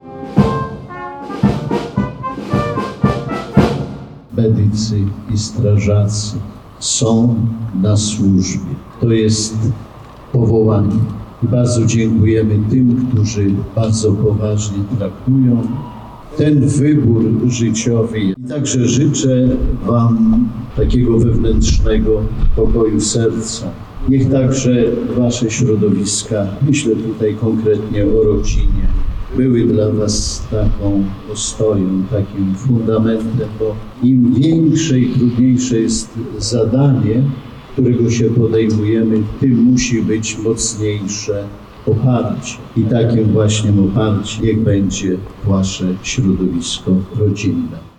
W piątek 24 .05 na terenie Jednostki Ratowniczo – Gaśniczej numer 5 w Warszawie odbyły się uroczyste obchody Dnia Strażaka.
Biskup Romuald Kamiński, ordynariusz diecezji warszawsko – praskiej uczestniczył podkreślał szczególną rolę strażaków w społeczeństwie.